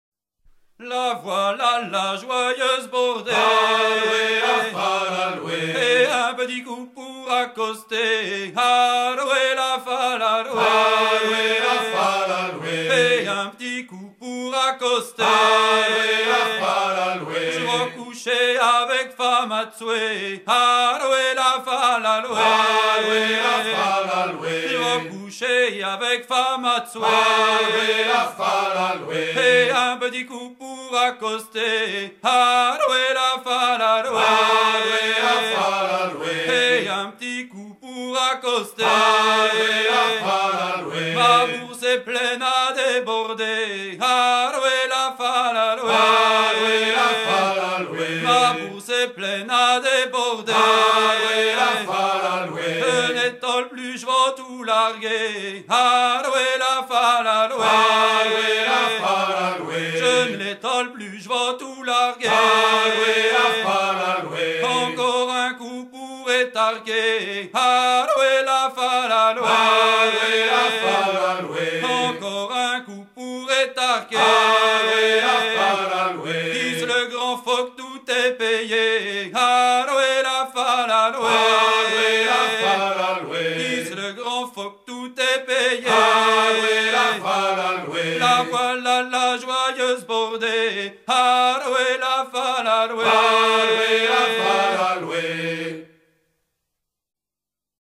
assemblage de couplets à partir de collectage et d'improvisations des chanteurs
Usage d'après l'analyste circonstance : maritimes
Genre laisse
Pièce musicale éditée